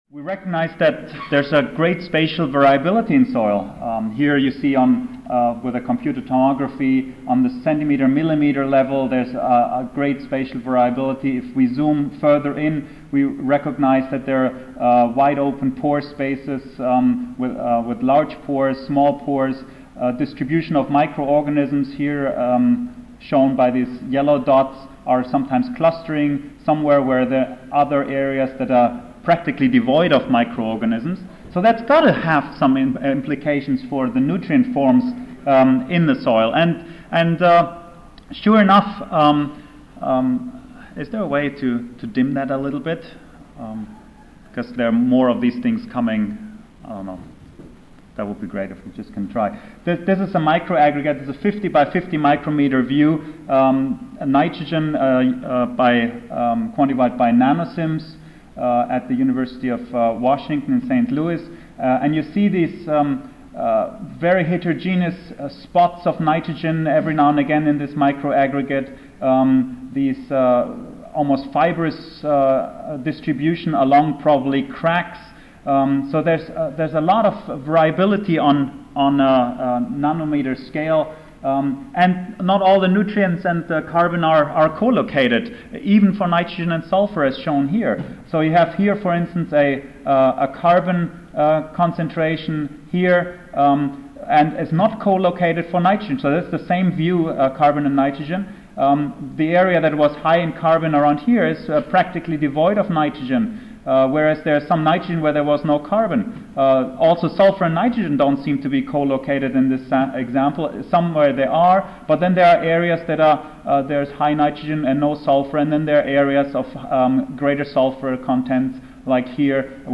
Back to Symposium--Advanced Analytical Methods for Understanding the Chemistry of Nutrient Elements in Soils: I Back to S11 Soils & Environmental QualityBack to The ASA-CSSA-SSSA International Annual Meetings (November 4-8, 2007)